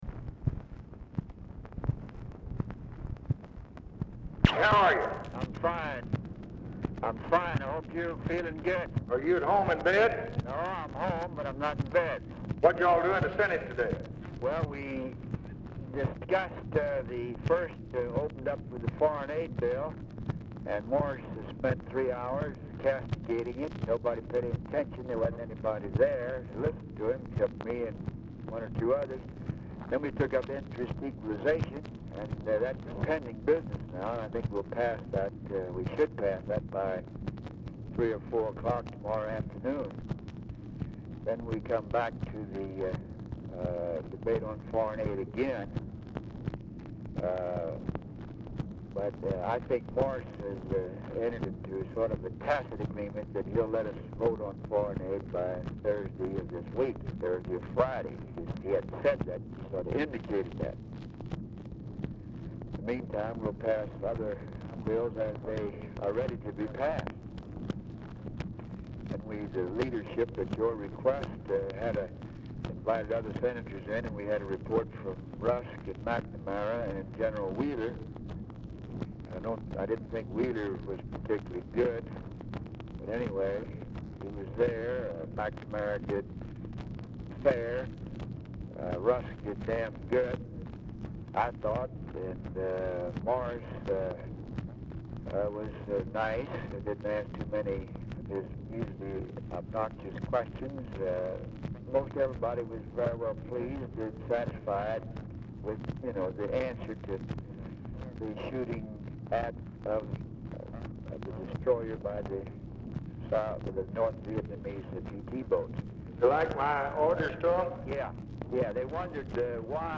Format Dictation belt
Location Of Speaker 1 Oval Office or unknown location
Specific Item Type Telephone conversation Subject Business Congressional Relations Defense Foreign Aid International Economic Policy Legislation Taxes Vietnam Welfare And War On Poverty